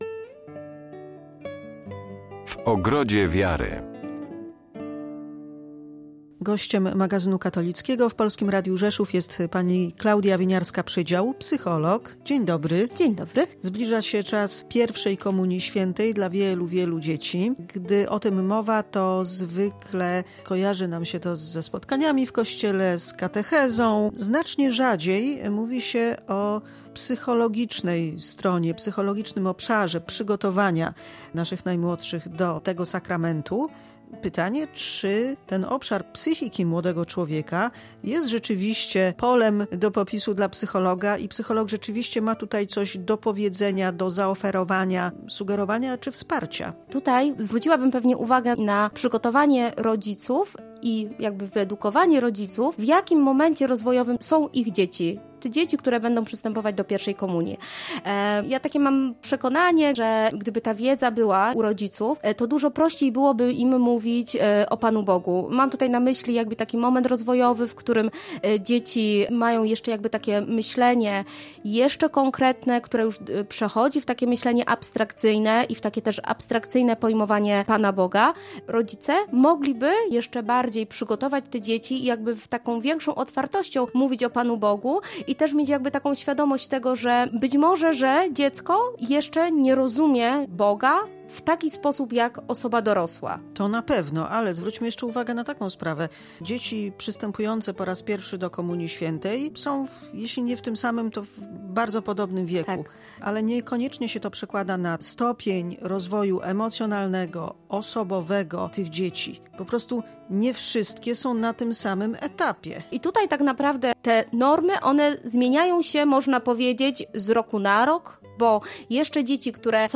Rozmowa z psychologiem